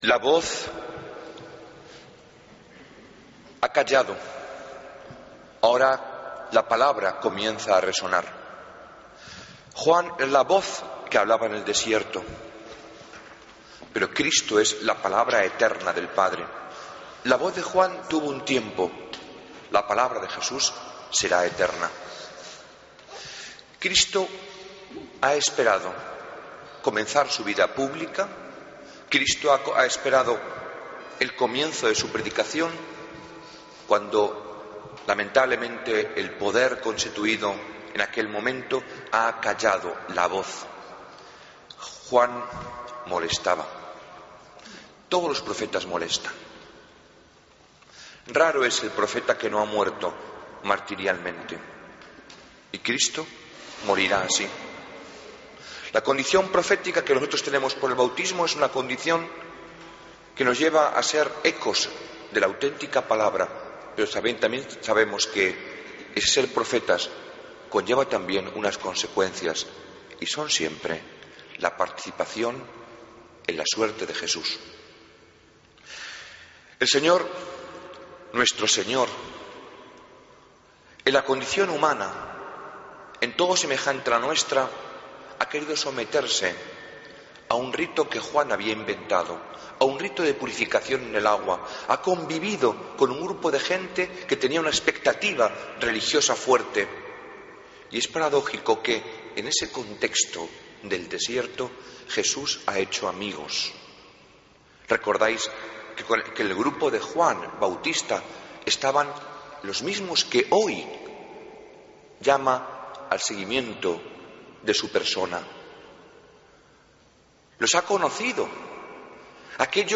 Homilía del 26 de Enero de 2014